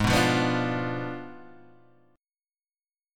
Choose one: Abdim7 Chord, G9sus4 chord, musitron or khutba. Abdim7 Chord